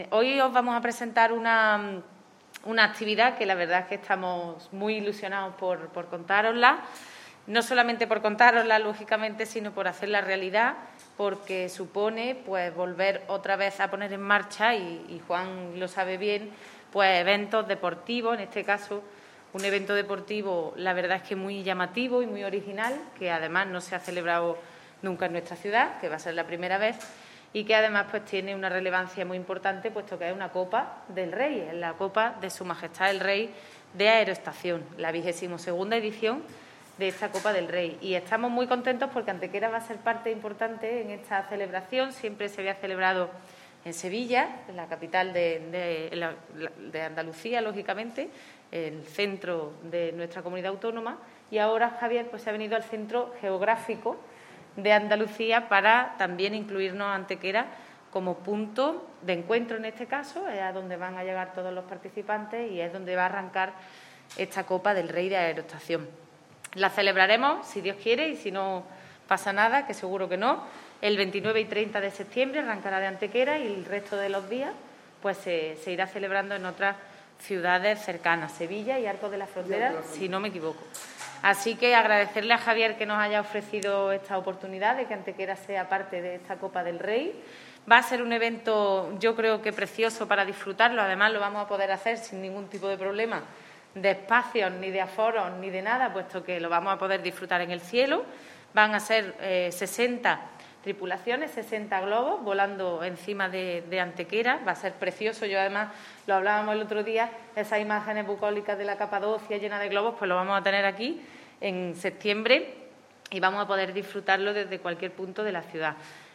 La teniente de alcalde delegada de Turismo, Ana Cebrián, y el teniente de alcalde de Deportes, Juan Rosas, han confirmado hoy en rueda de prensa que Antequera acogerá durante los próximos días 29 y 30 de septiembre el inicio de la XXII Copa de S.M. el Rey de Aerostación.
Cortes de voz